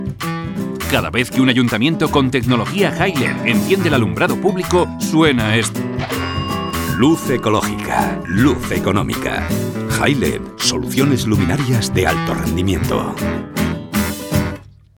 Cuña Publicitaria Radio 2